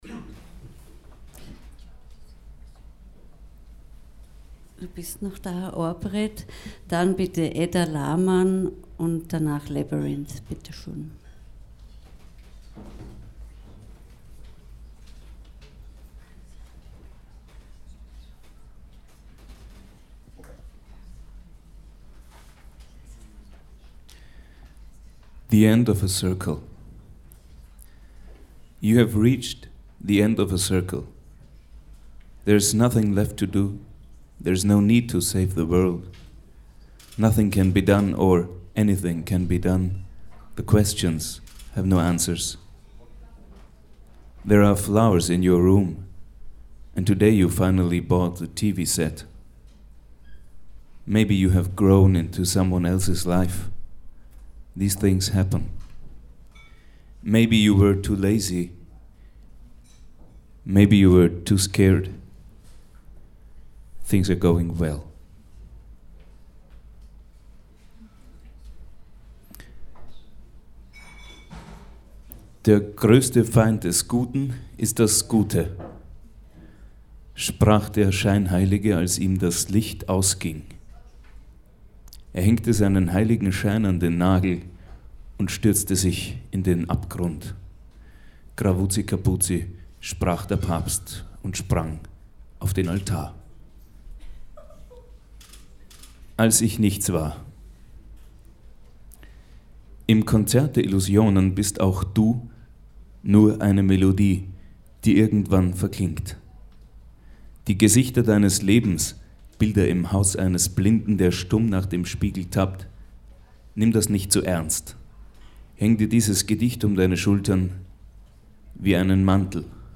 kurz danach, am 23. februar, traten künstlerinnen und künstler ihm zu ehren im celeste in wien auf.
tonaufnahmen: amann studios wien